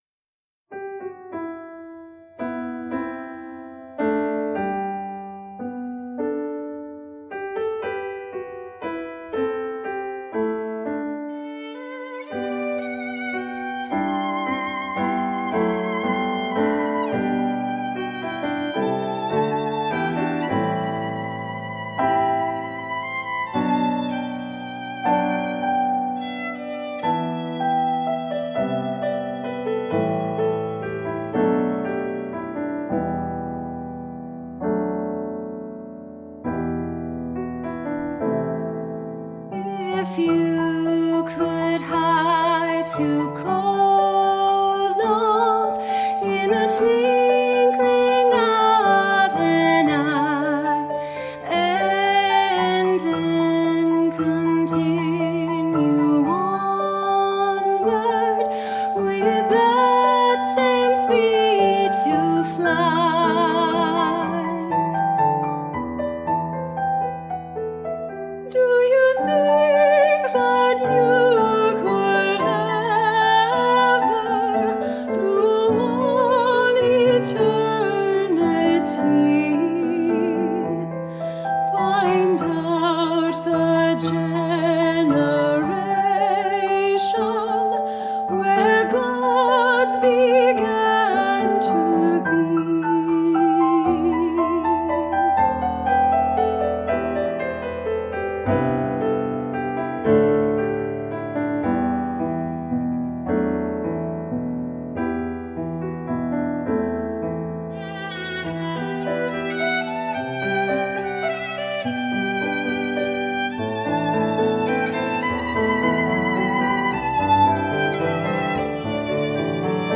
for piano, violin or flute, and SA
Violin, Piano, Violin or Flute and SA